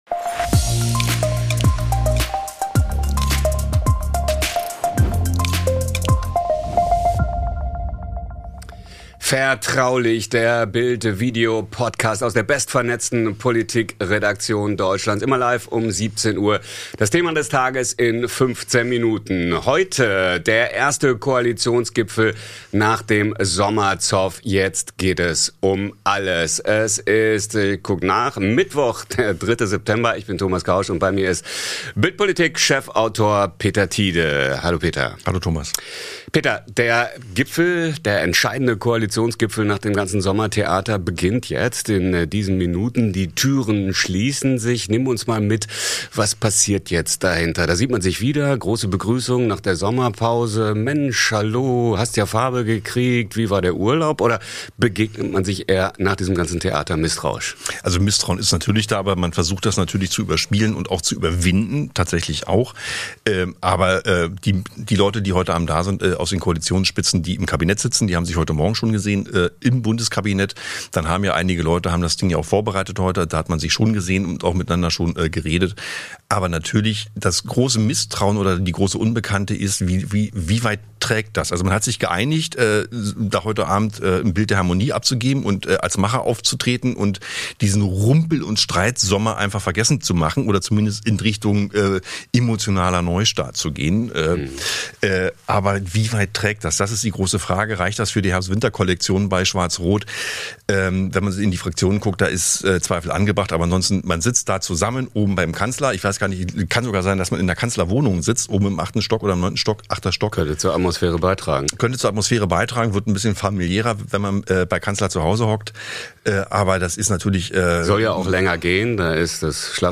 Der Koalitionsgipfel nach dem Sommerzoff ~ Vertraulich - der tägliche Politik-Talk Podcast